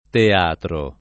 teatro [ te # tro ] s. m.